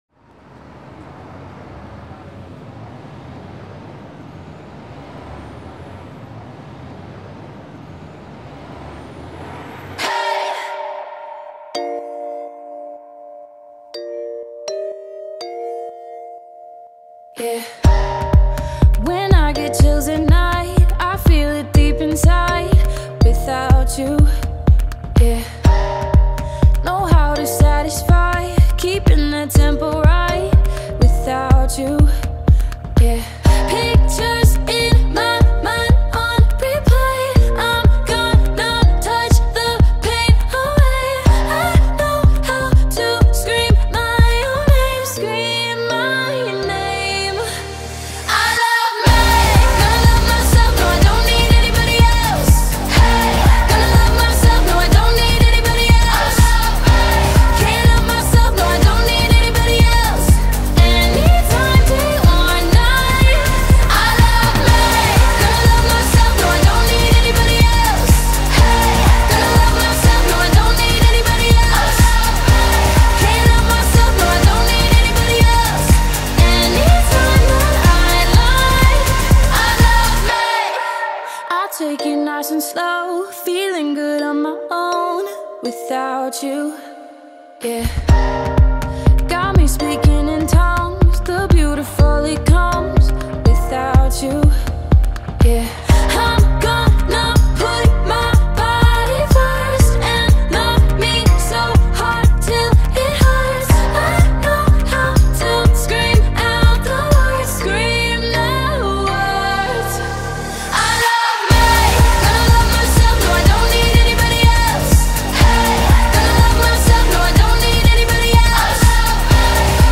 اهنگ انگیزشی خارجی با ترجمه